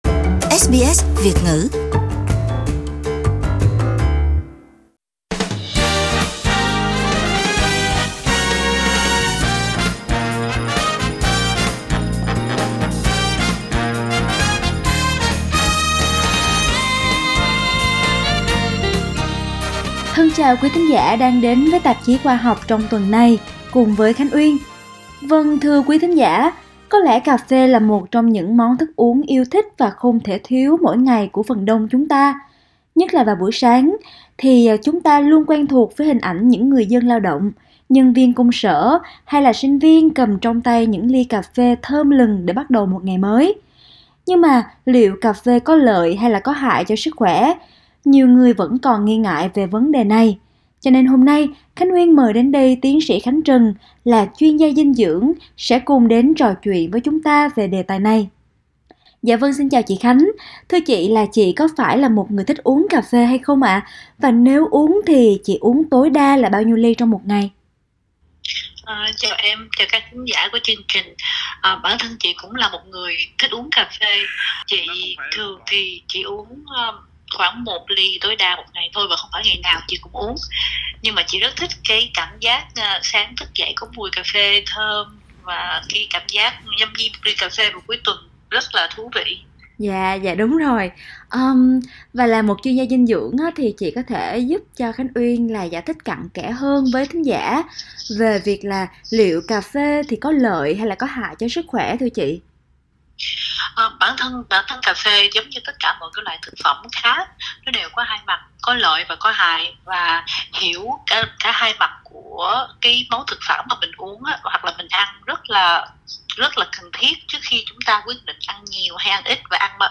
Nhưng liệu cà phê có lợi hay hại cho sức khoẻ? Cùng trò chuyện với chuyên gia dinh dưỡng